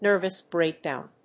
nérvous bréakdown